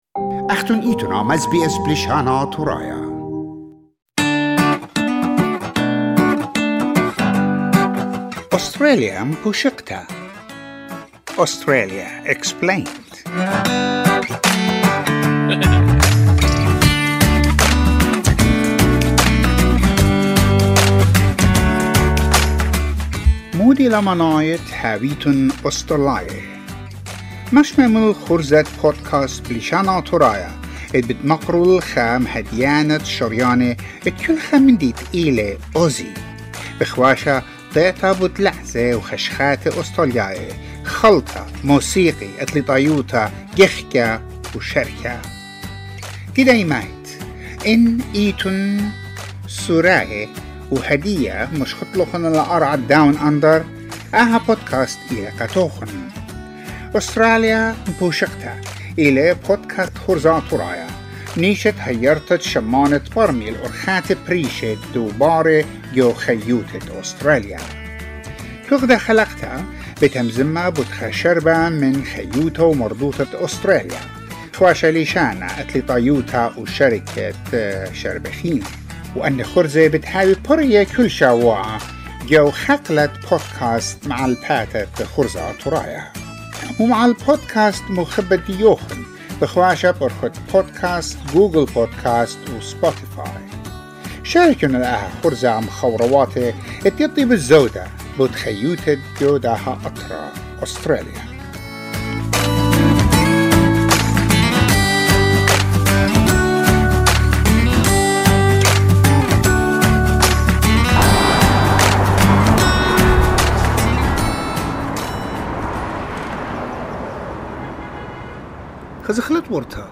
In this episode of the Australia Explained podcast we also hear the experiences of some migrants visiting the pub for the first time.